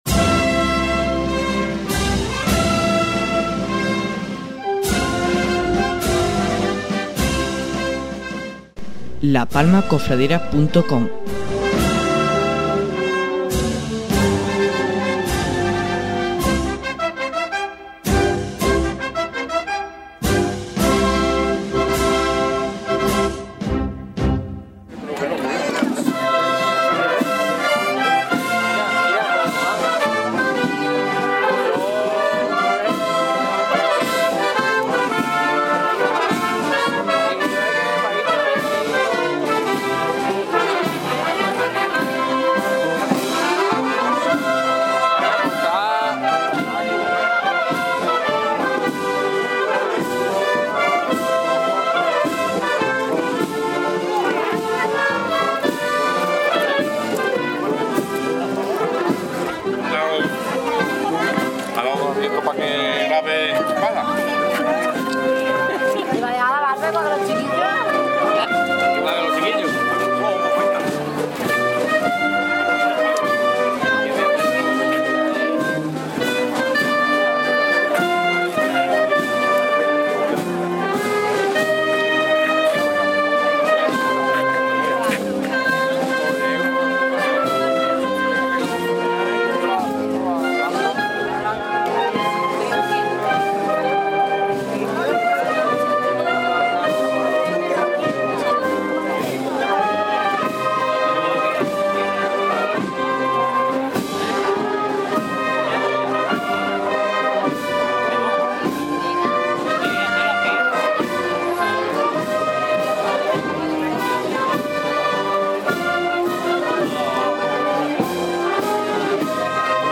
Así sonaba en su estreno la marcha